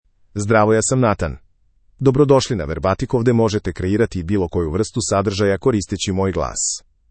Nathan — Male Serbian AI voice
Nathan is a male AI voice for Serbian (Serbia).
Voice sample
Listen to Nathan's male Serbian voice.
Nathan delivers clear pronunciation with authentic Serbia Serbian intonation, making your content sound professionally produced.